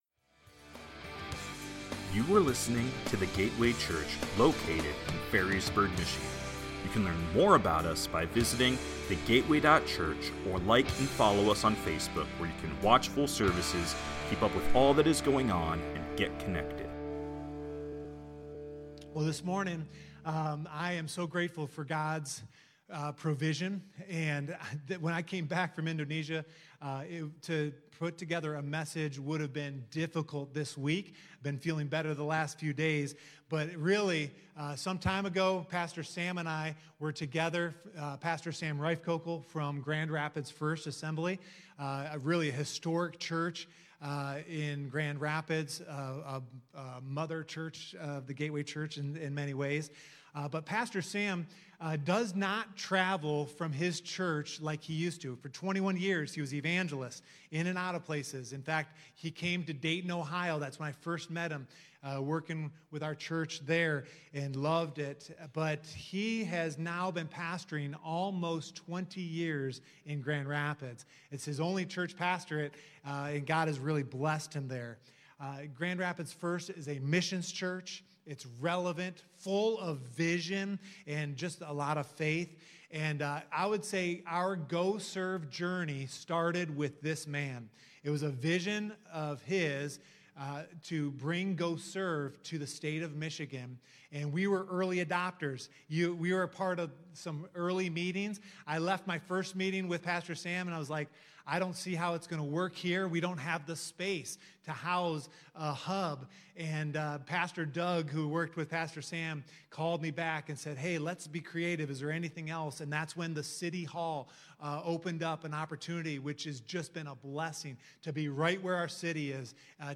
From Series: "Stand Alone Sermon"